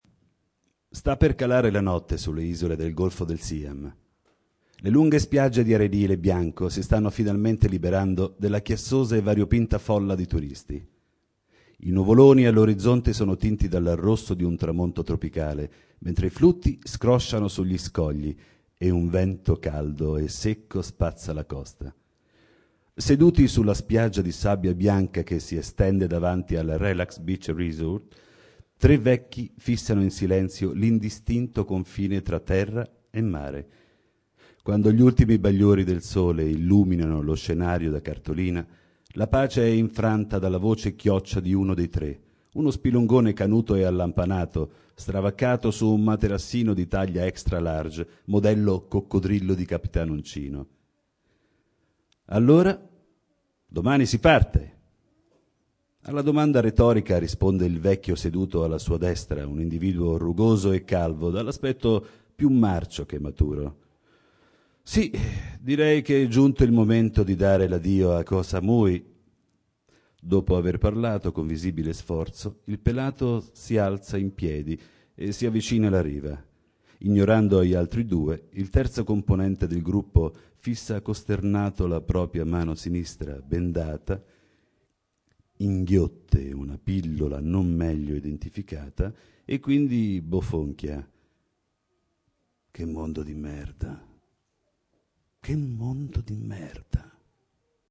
Letture di brani scelti